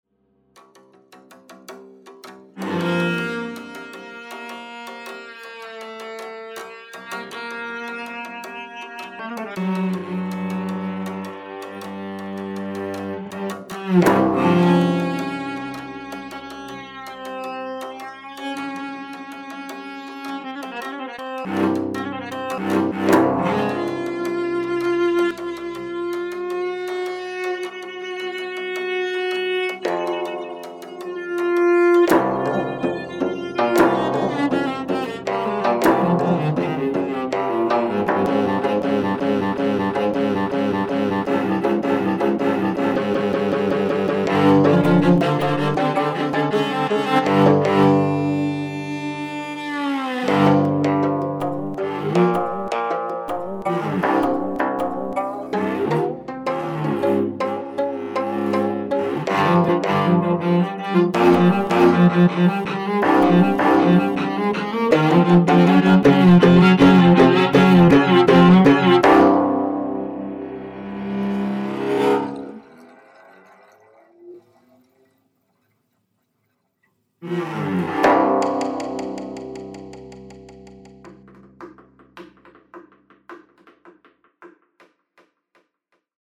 geomungo & cello 거문고와 첼로